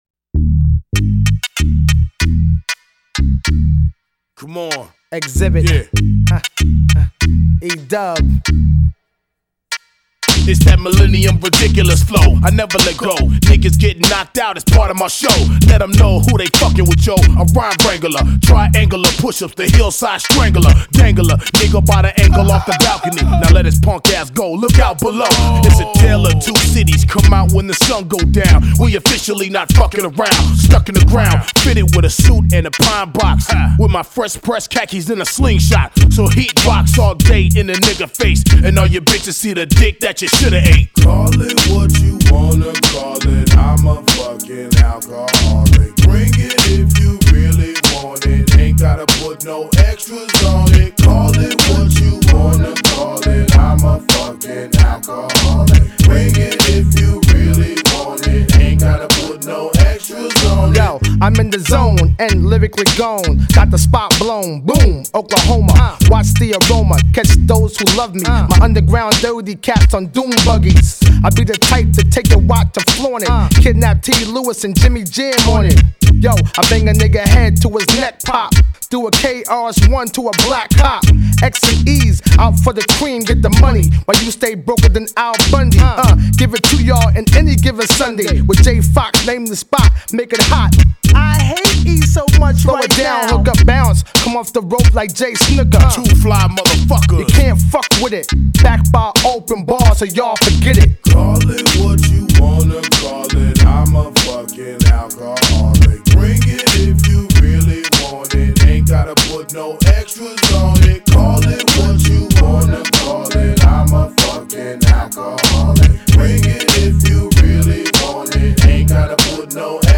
Назад в (rap)...